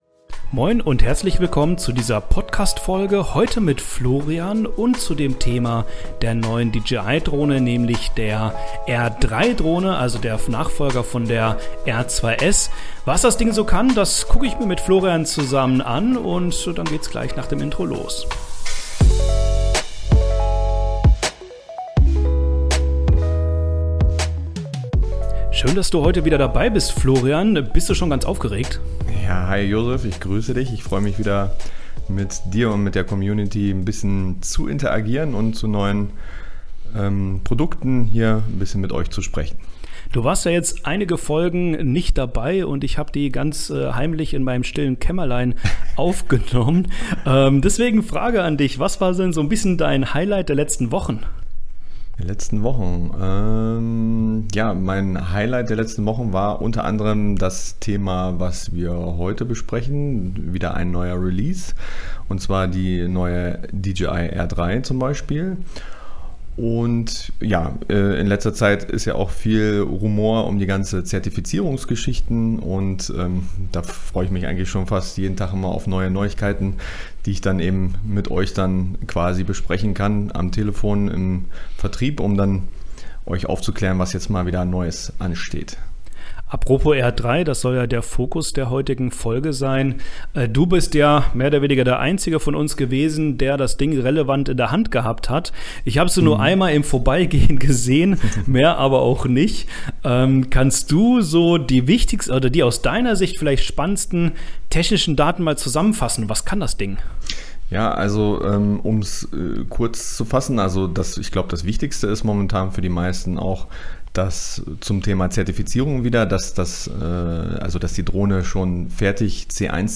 Technik-Talk